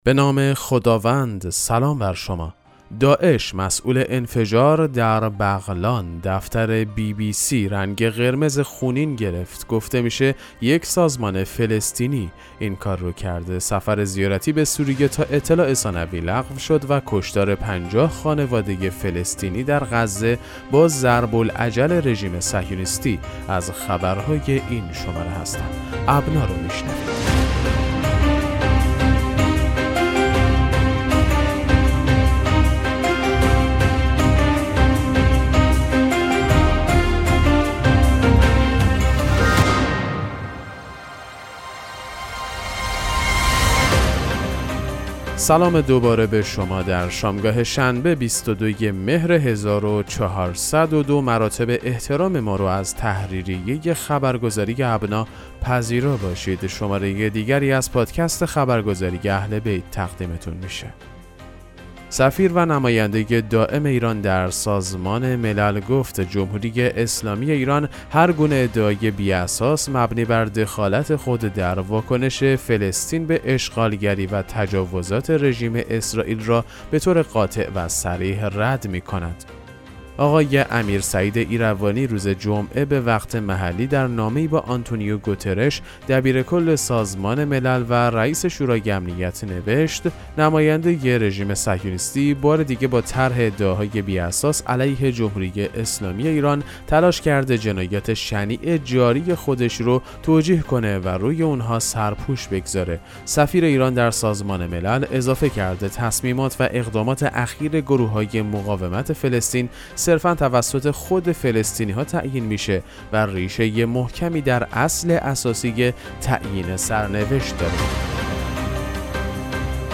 پادکست مهم‌ترین اخبار ابنا فارسی ــ 22 مهر 1402